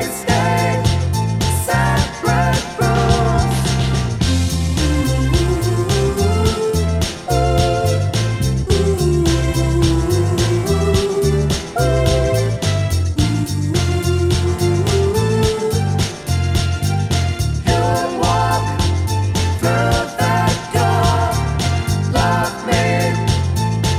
no kit bass or main guitar Soul / Motown 2:55 Buy £1.50